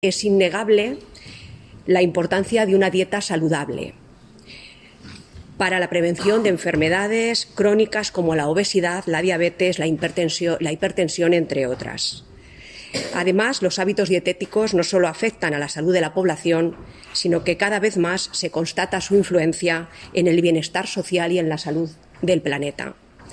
Declaraciones de la delegada provincial de Sanidad en Guadalajara, Pilar Cuevas